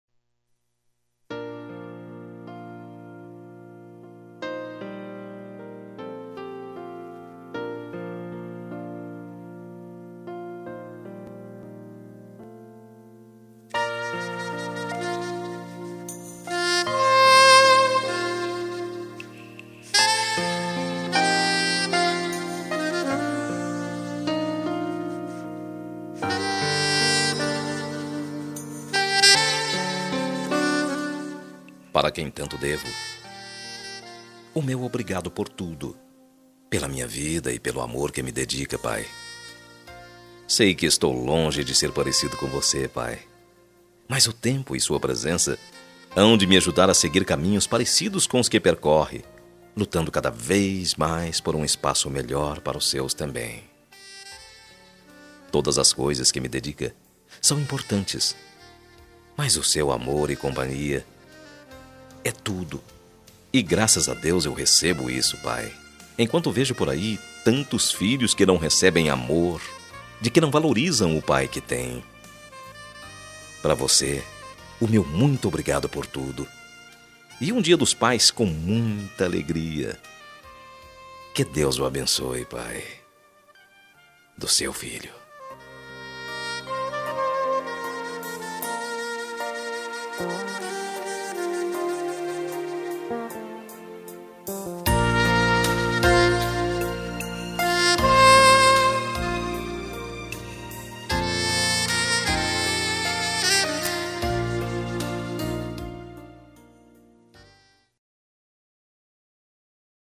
Dia Dos Pais Voz Masculina